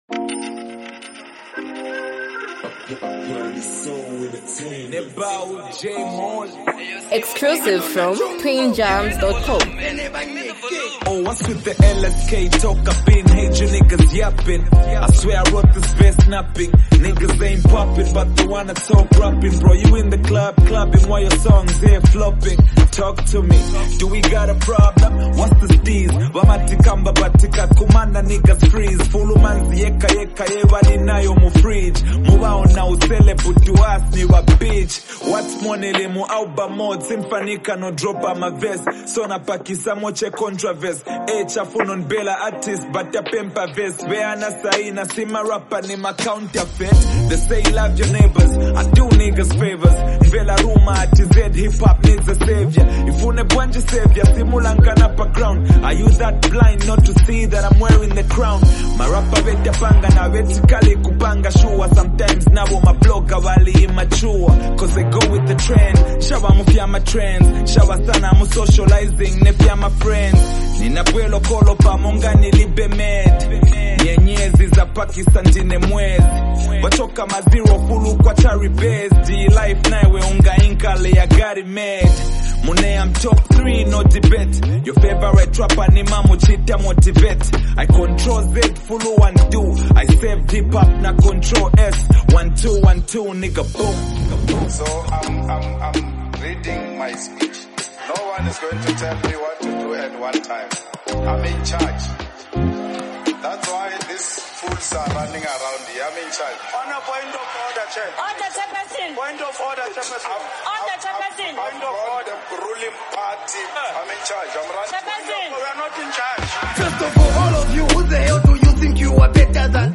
bold and energetic hip-hop anthem
gritty and raw delivery